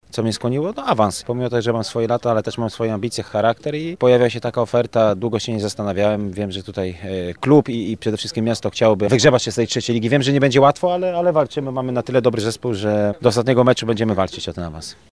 35-letni napastnik opowiada o powodach jego przyjścia do Avii: